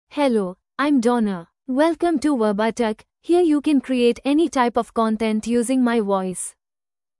FemaleEnglish (India)
DonnaFemale English AI voice
Donna is a female AI voice for English (India).
Voice sample
Donna delivers clear pronunciation with authentic India English intonation, making your content sound professionally produced.